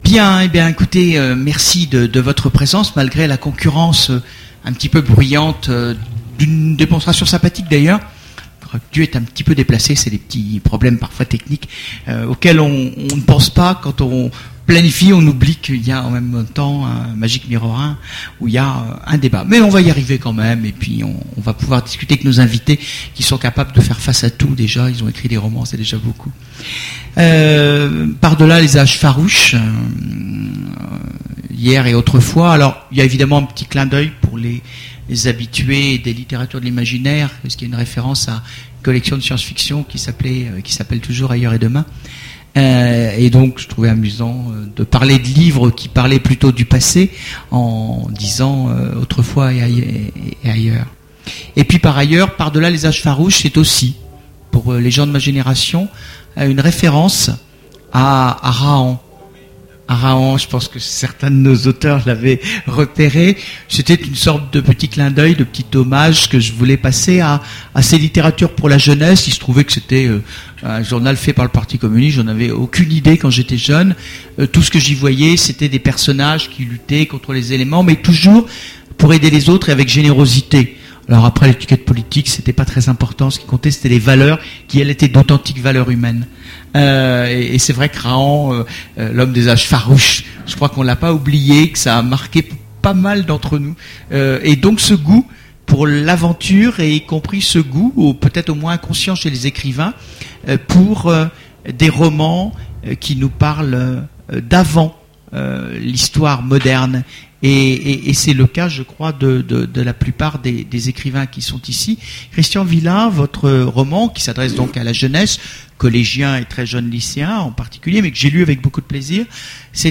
Voici l'enregistrement de la conférence Par-delà les âges farouches ! aux Imaginales 2010